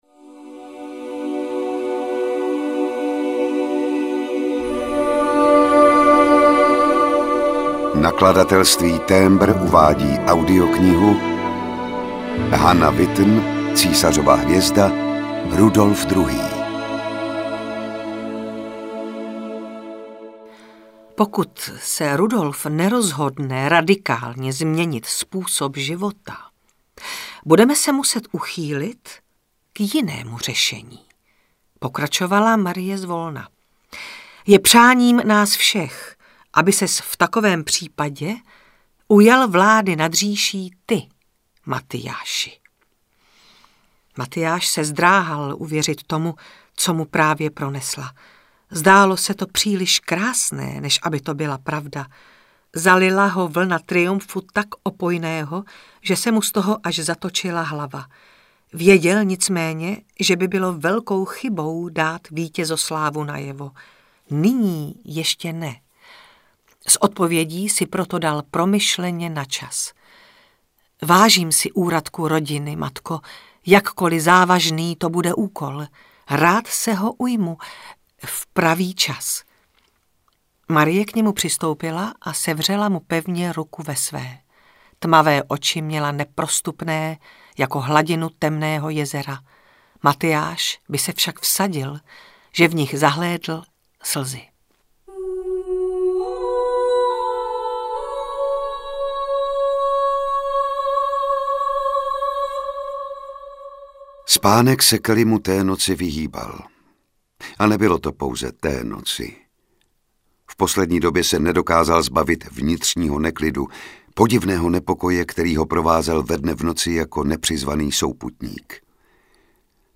Ukázka z knihy
• InterpretSimona Postlerová, Jan Šťastný